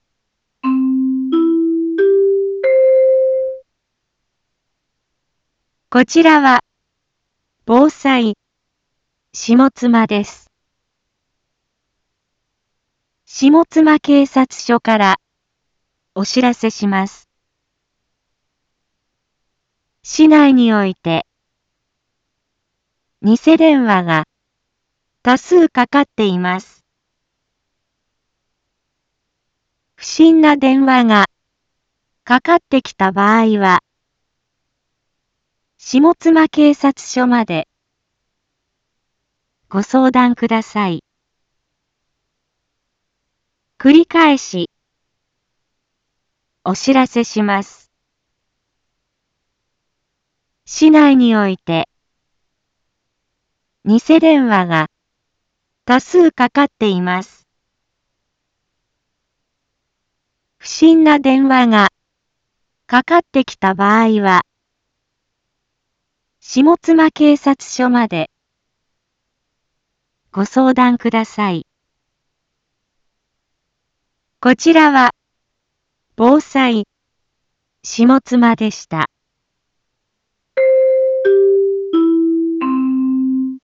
一般放送情報
Back Home 一般放送情報 音声放送 再生 一般放送情報 登録日時：2021-07-12 12:31:27 タイトル：ニセ電話詐欺にご注意を インフォメーション：こちらはぼうさいしもつまです。